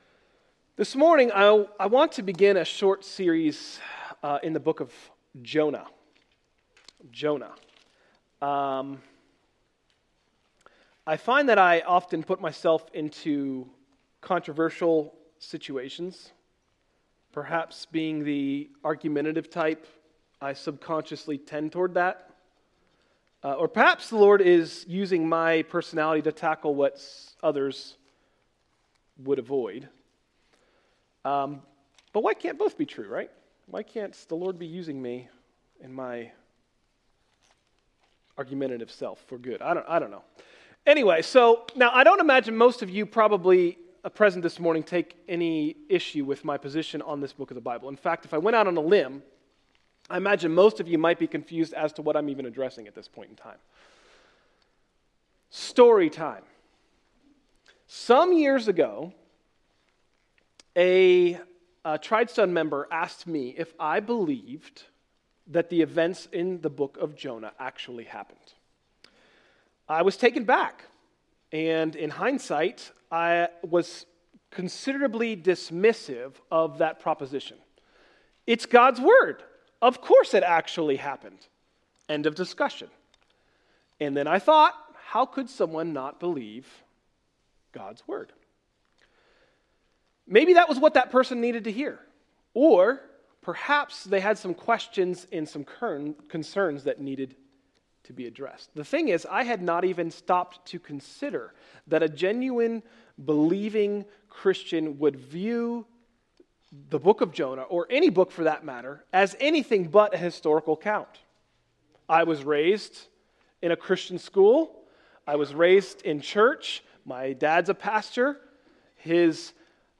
In this sermon we tackle chapter 1, looking at the background to Jonah and Ninevah, and considering the implications of being obedient to God.